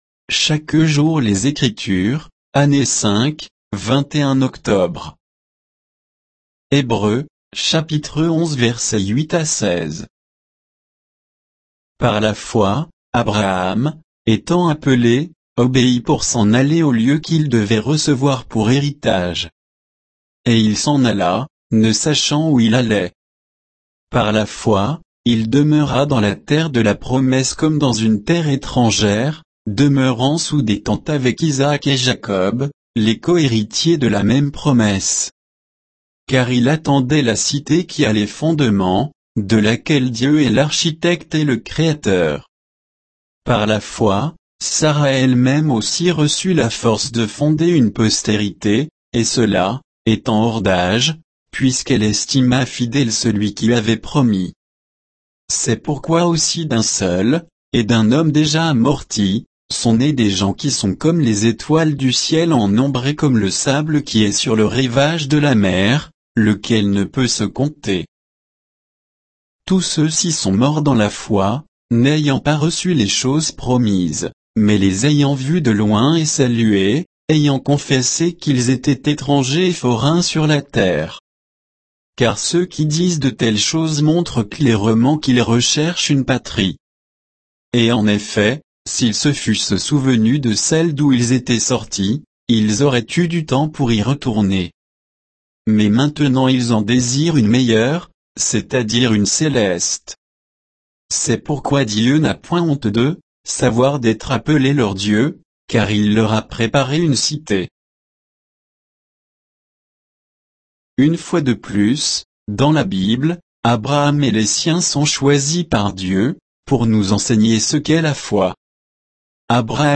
Méditation quoditienne de Chaque jour les Écritures sur Hébreux 11, 8 à 16